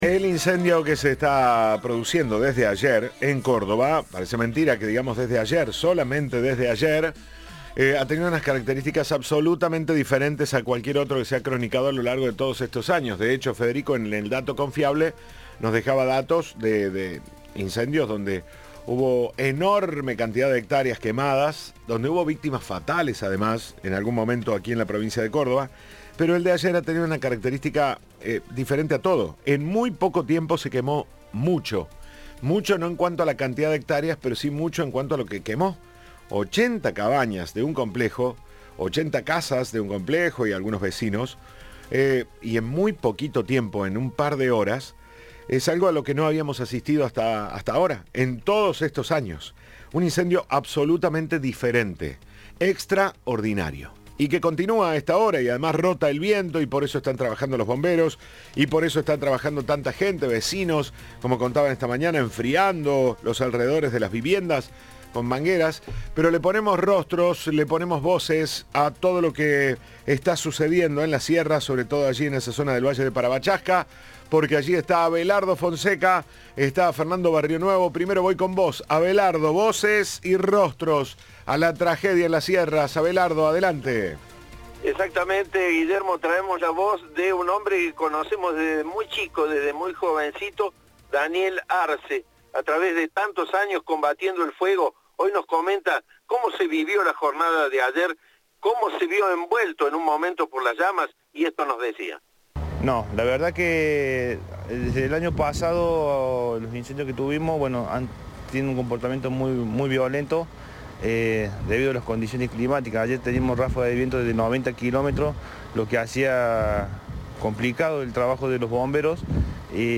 Audio. Testimonios en los incendios: la frustración de un bombero y una rotación milagrosa